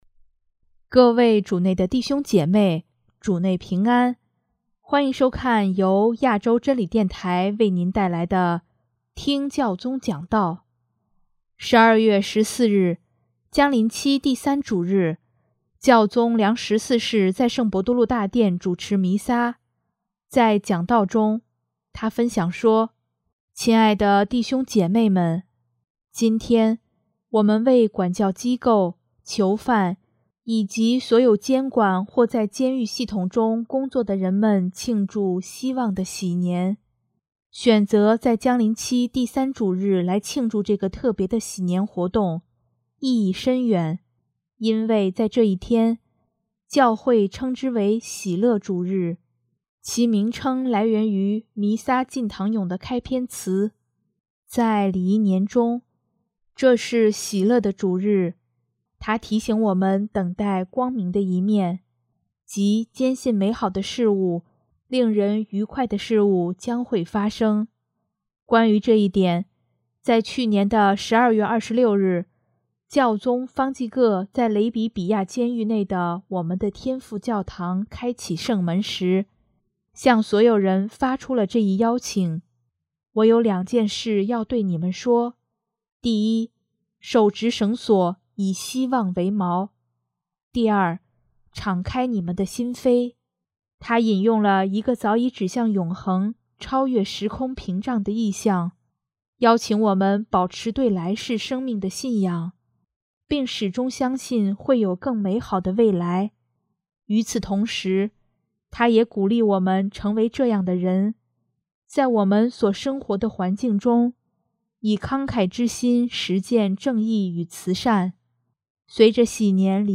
首页 / 听教宗讲道/ 新闻/ 教宗良十四世
12月14日，将临期第三主日，教宗良十四世在圣伯多禄大殿主持弥撒，在讲道中，他分享说：